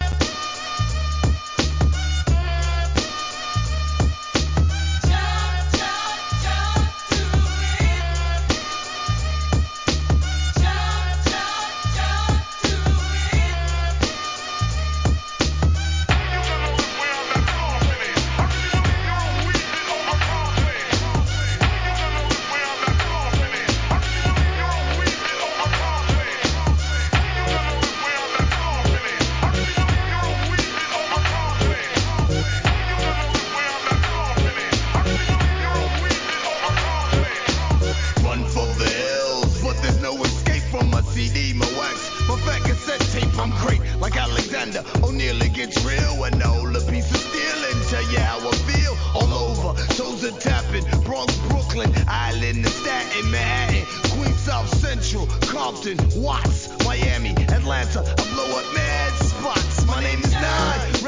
HIP HOP/R&B
(BPM 86.9)